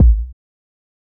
Kick (2).wav